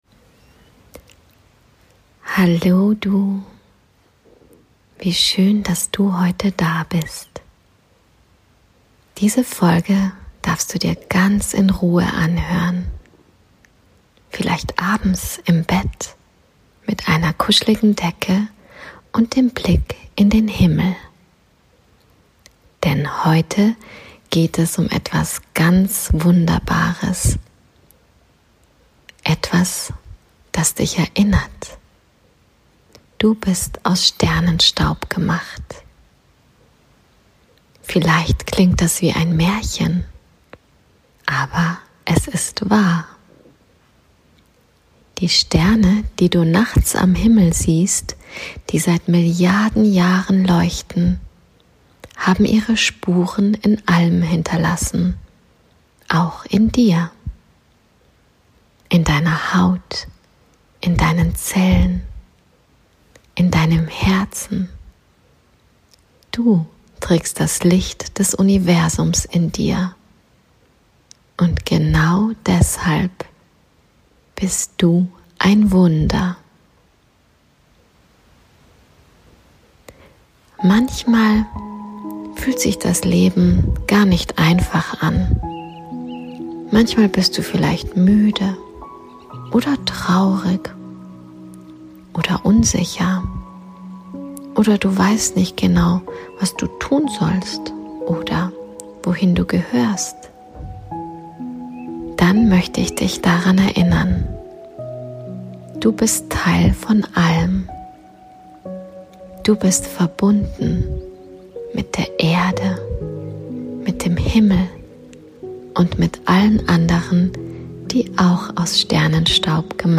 Mit sanften Worten, einer geführten Traumreise und ganz viel Herz begleitet Dich diese Episode in die Ruhe, ins Vertrauen und in die Selbstliebe.